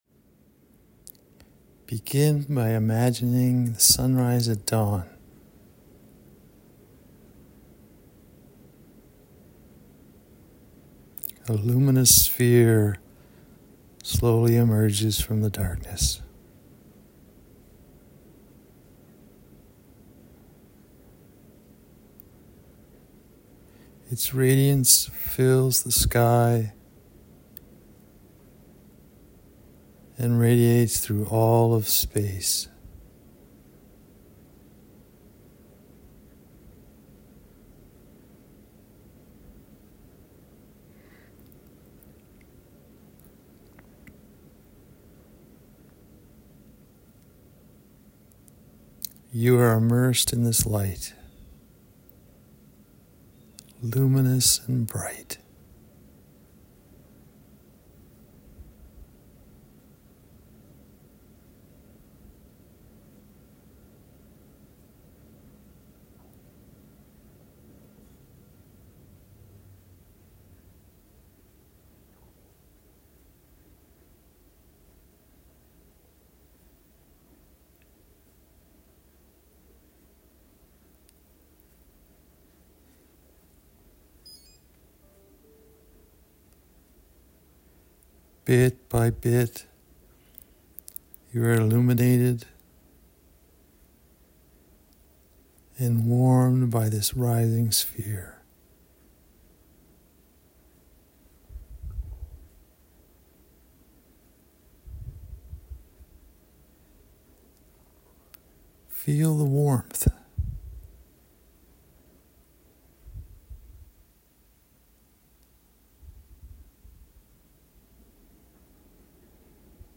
The Meditation